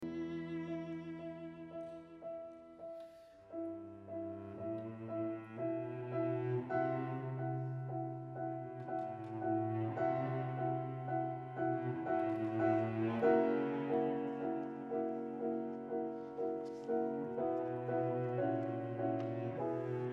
アダージョ　３/４拍子　ホ長調
音楽はピアノのEの連打から今までとは別の低音域の声が　E moll で語りだす。誰かが何か不吉な知らせを告げに来たかのような不安な響きである。
音源１３）ピアノの８分音符は緊迫したE-Fisを執拗に鳴らすが　（*３）、すぐに安らかなE durに回帰して行く。